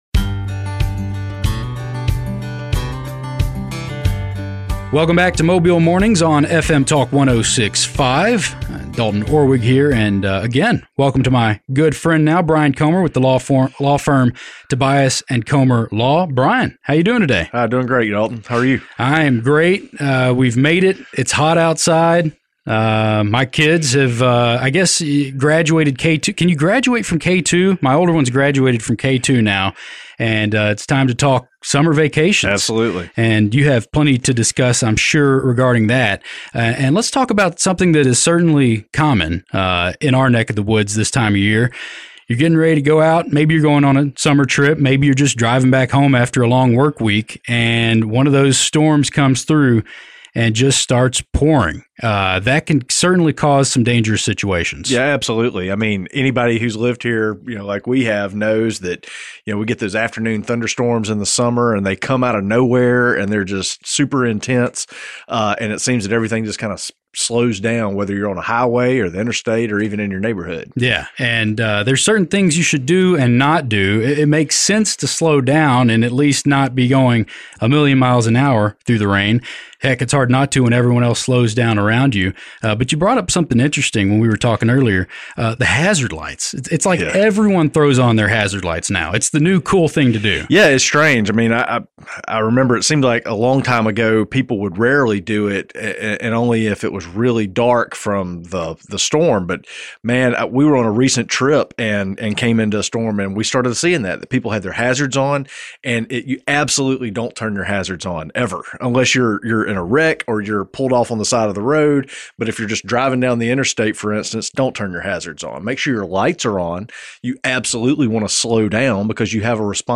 Listen to their informative conversation: